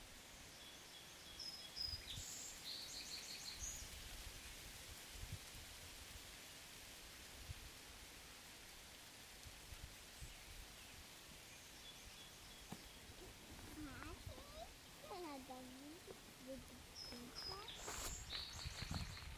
描述：鸟儿歌唱
标签： 鸟鸣声 气氛 户外 线索 自然 现场录音
声道立体声